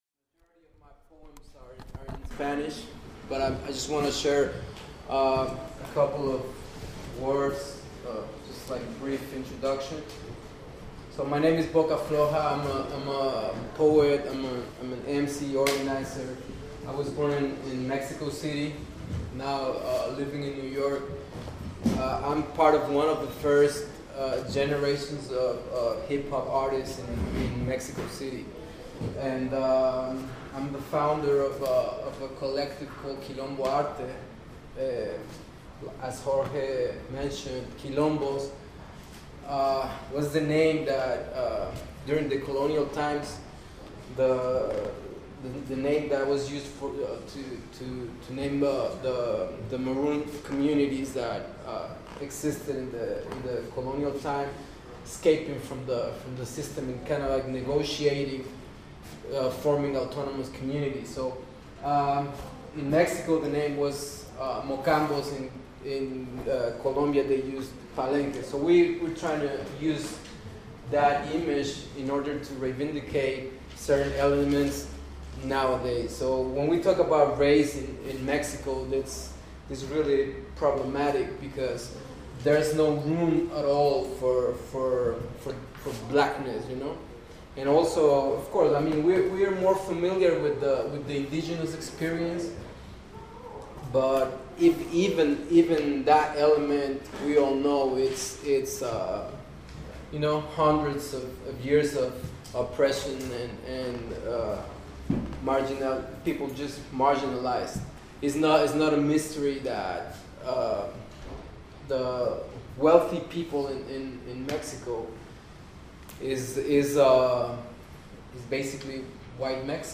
Spoken Word Performance at The Holdout (Oakland)